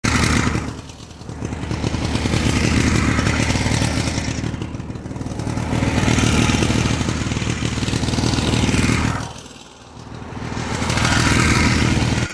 دانلود آهنگ موتور 2 از افکت صوتی حمل و نقل
دانلود صدای موتور 2 از ساعد نیوز با لینک مستقیم و کیفیت بالا
جلوه های صوتی